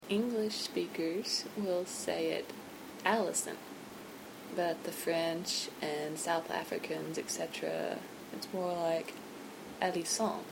the accent is on the first syllable, not the last. also the a sound is ummmm deeper sounding?